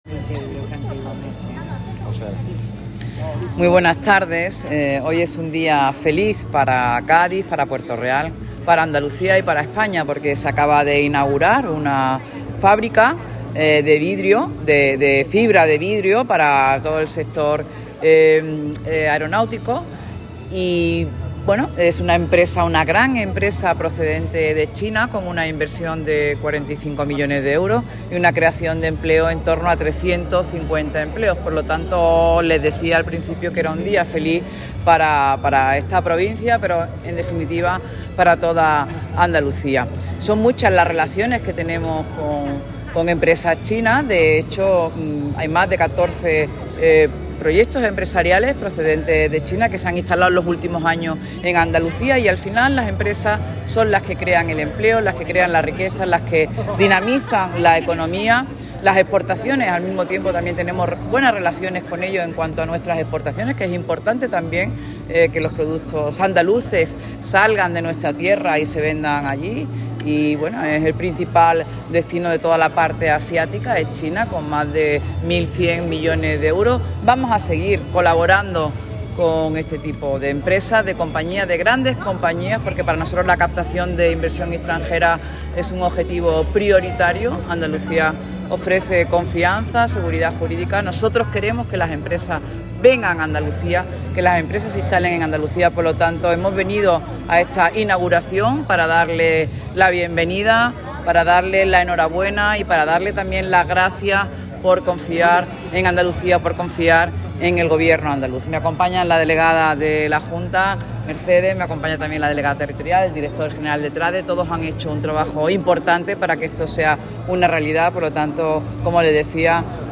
Audio de la Consejera en la inauguración (m4a)